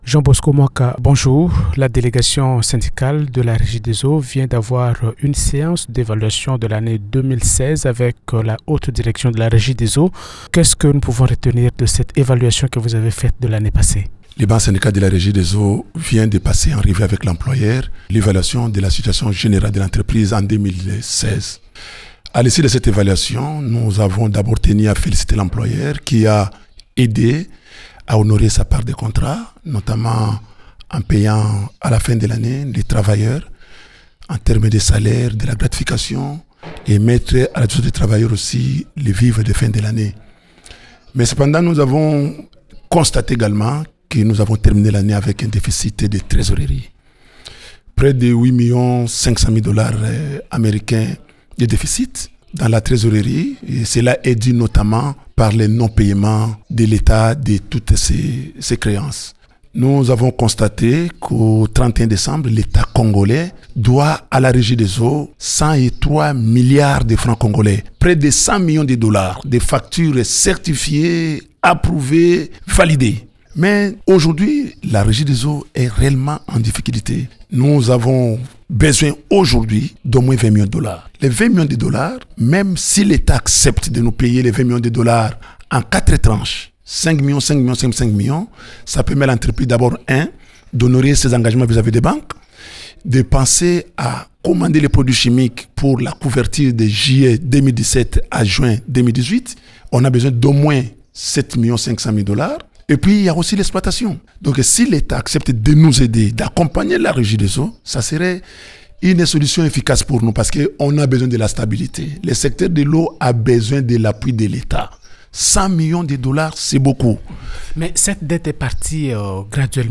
est l’invité de Radio Okapi